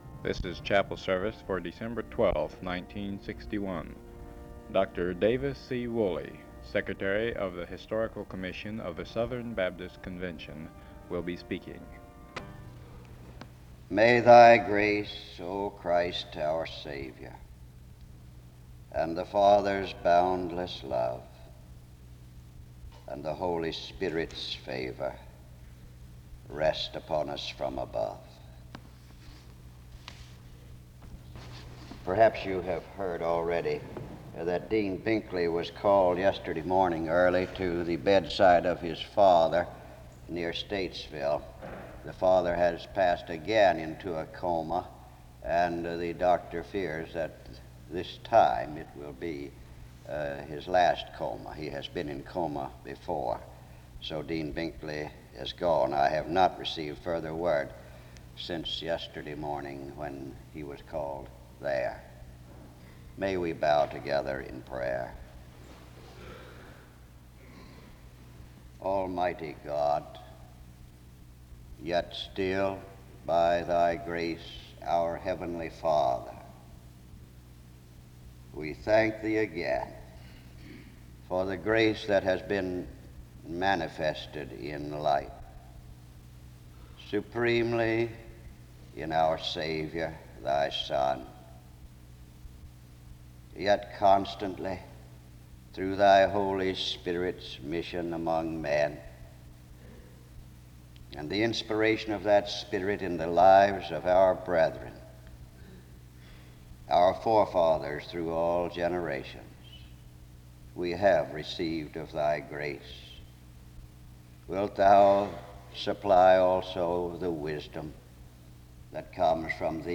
Download .mp3 Description The service begins with prayer (00:00-00:30). A brief word precedes another prayer (00:31-03:51).